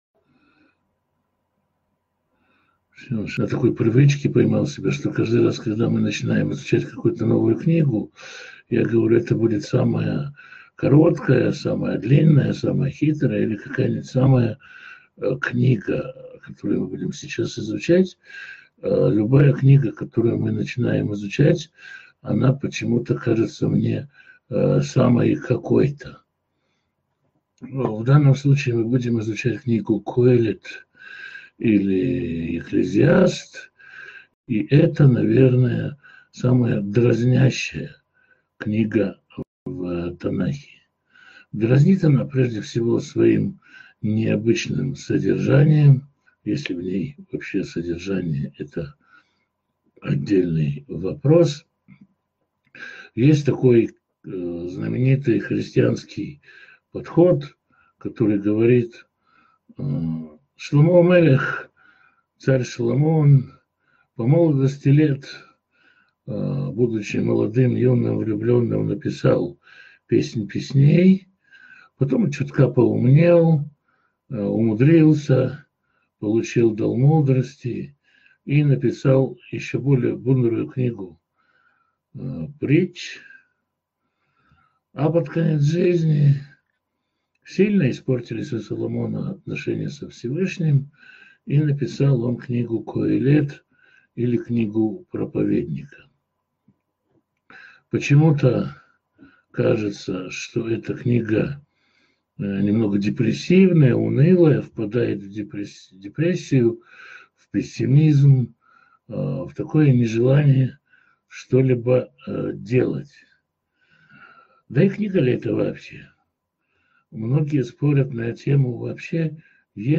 Коѓелет Беседа 1.mp3